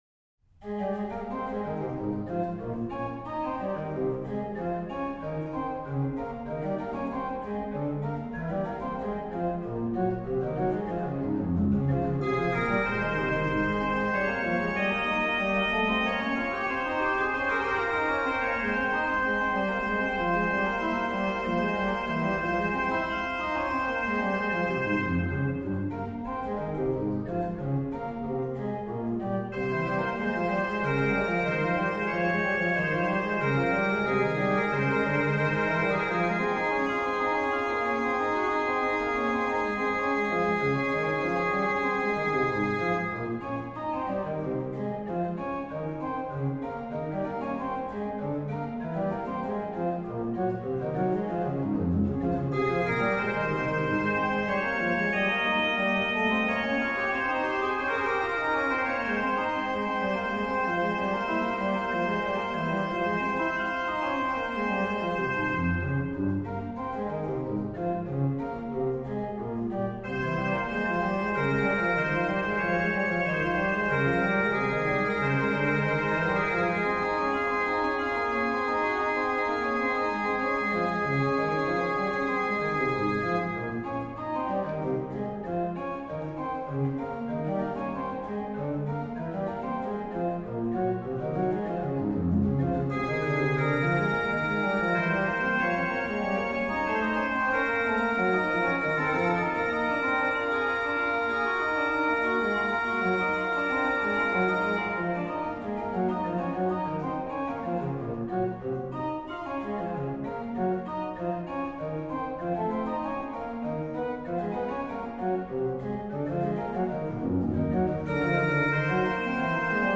Subtitle   Bicinium
Registration   rh: HW: Rfl8, Spz4, CorIII
lh: OW: Qnt8, Ged8, Pr4, Nas3